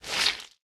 Minecraft Version Minecraft Version latest Latest Release | Latest Snapshot latest / assets / minecraft / sounds / block / sponge / absorb2.ogg Compare With Compare With Latest Release | Latest Snapshot
absorb2.ogg